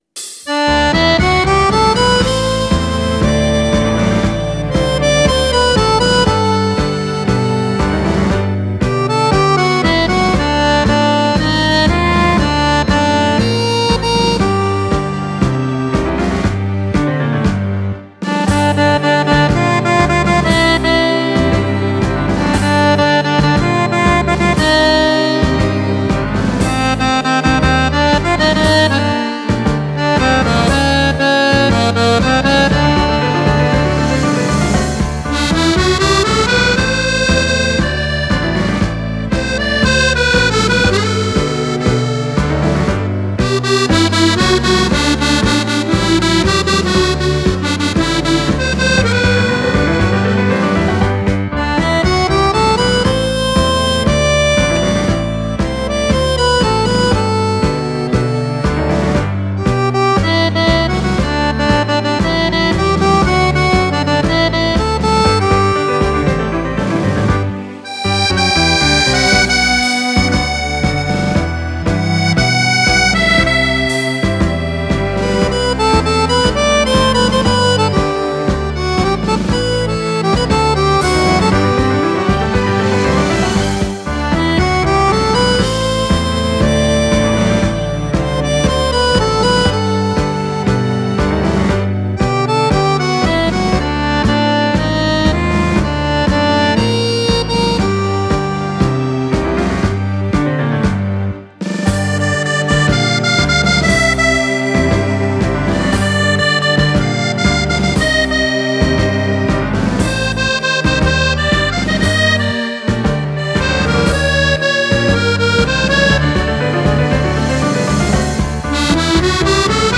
Tango
arrangiata a tango